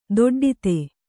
♪ doḍḍite